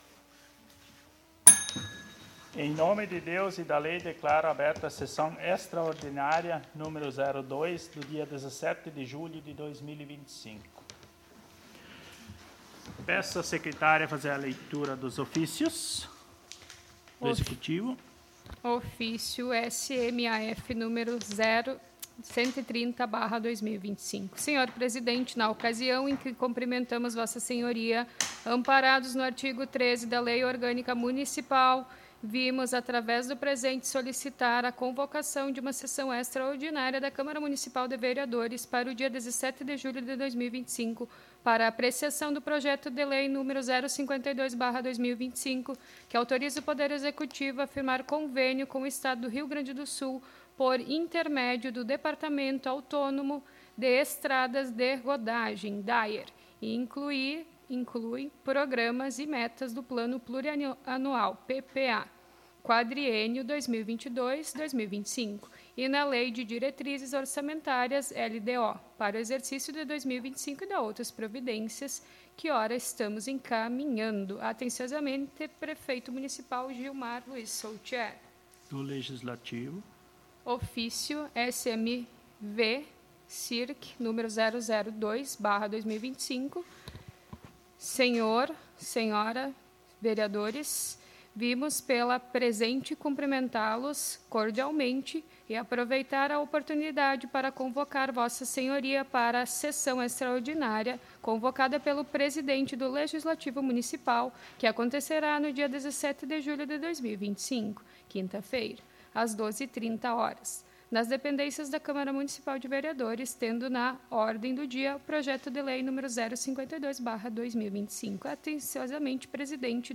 Aos 17 (dezessete) dias do mês de julho do ano de 2025 (dois mil e vinte e cinco), na Sala de Sessões da Câmara Municipal de Vereadores de Travesseiro/RS, realizou-se a Segunda Sessão Extraordinária da Legislatura 2025-2028, sob a Presidência do Vereador Adriano Steffler.